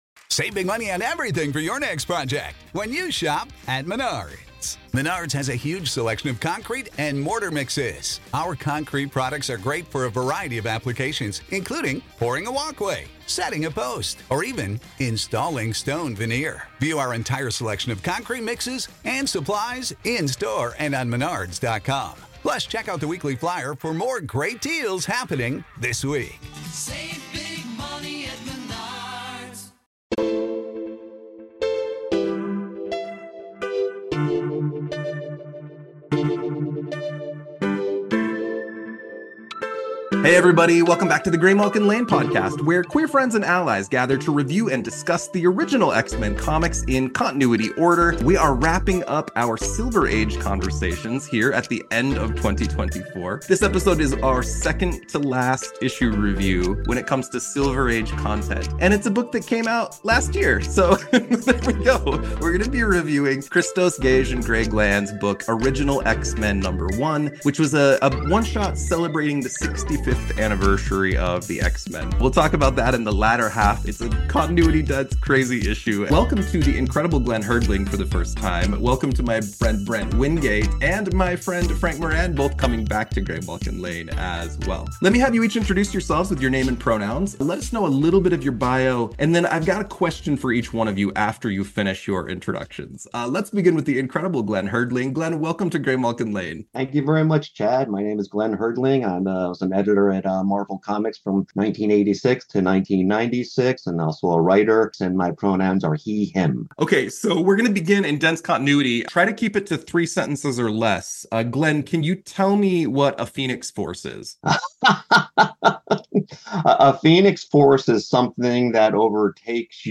Then a script reading of X-Men Unlimited 6!